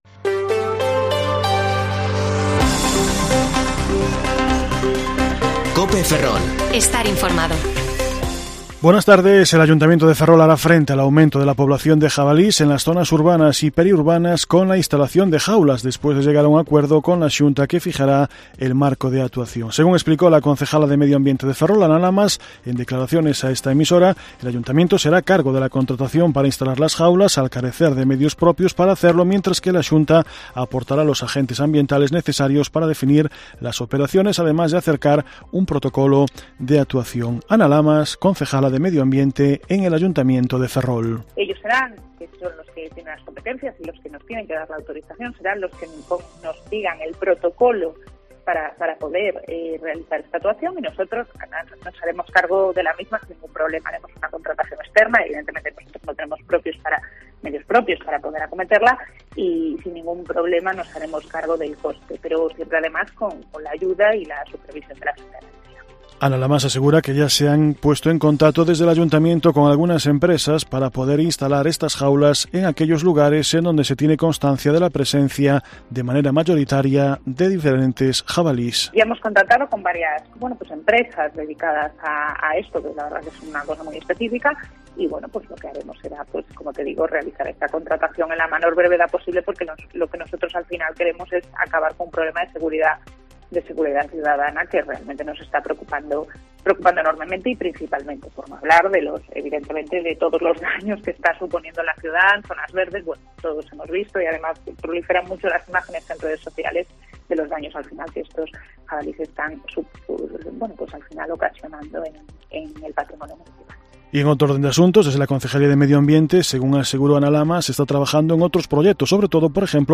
Informativo Mediodía COPE Ferrol 24/1/2022 (De 14,20 a 14,30 horas)